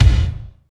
35.04 KICK.wav